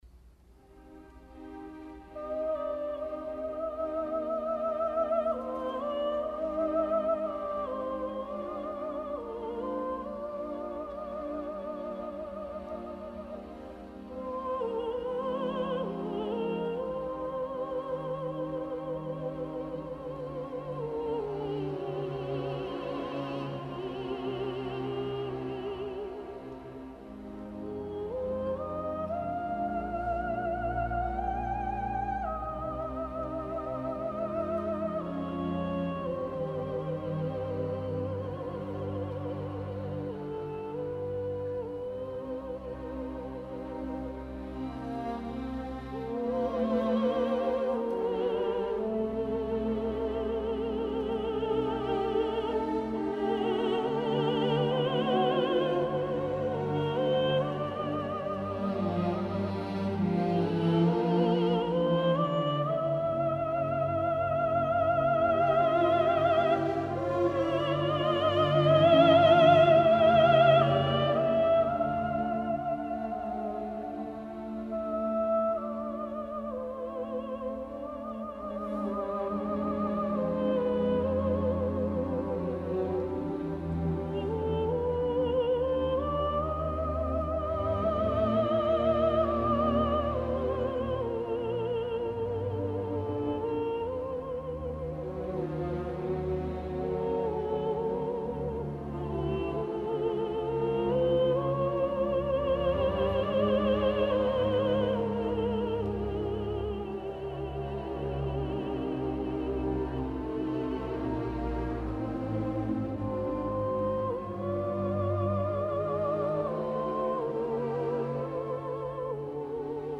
4. Вокализ Рахманинова.mp3